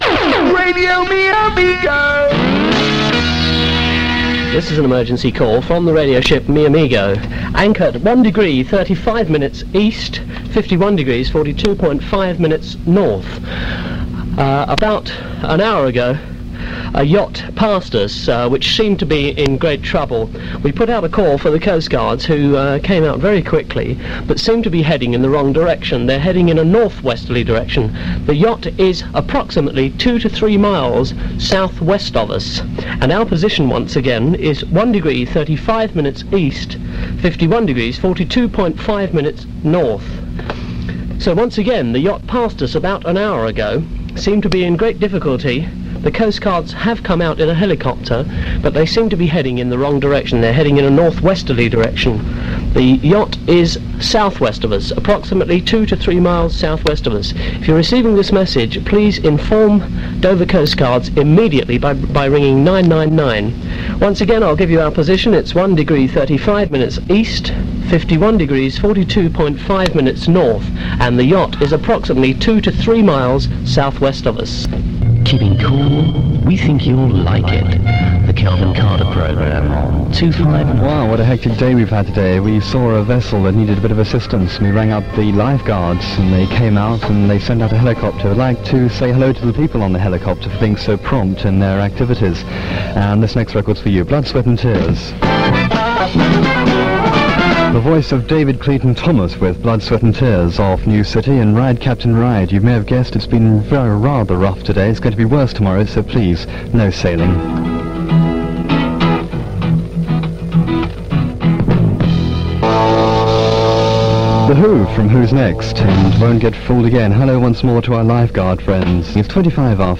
click to hear audio Radio Mi Amigo's programmes are interrupted with an appeal for assistance on behalf of a yacht in trouble and then